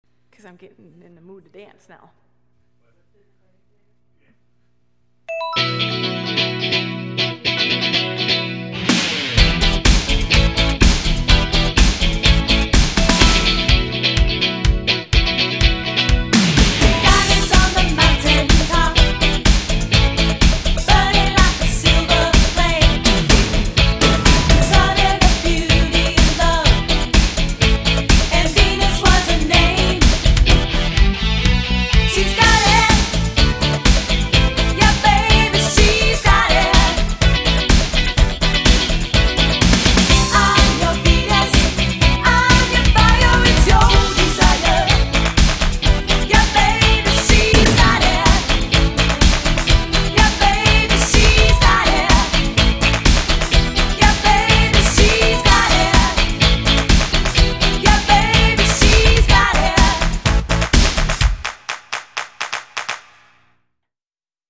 My drum parts
Dance/R&B/Disco